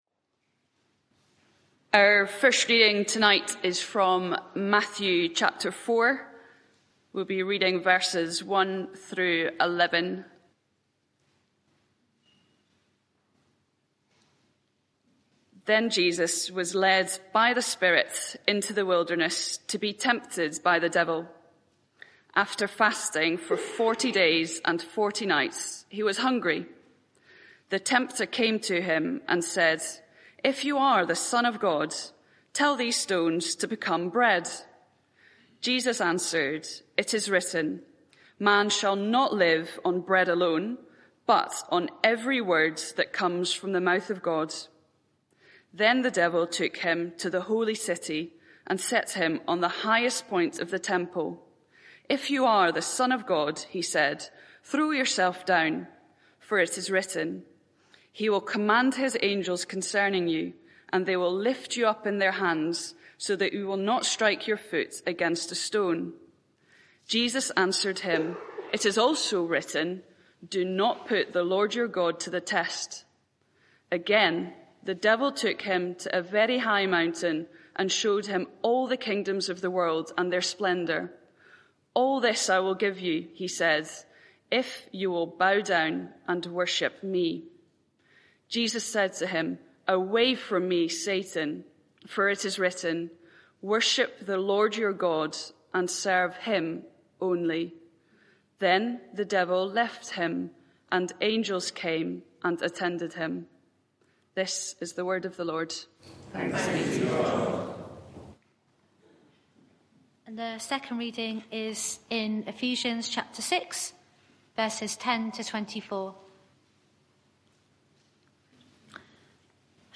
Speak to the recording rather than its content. Media for 6:30pm Service on Sun 14th Apr 2024 18:30